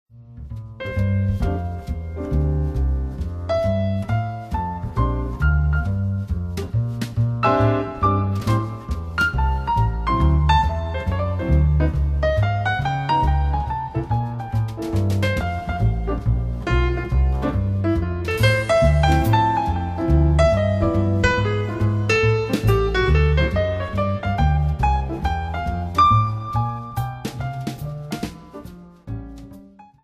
四、採用爵士樂中最受人喜愛的 鋼琴、鼓、貝斯 三重奏編制，演奏受人喜愛的旋律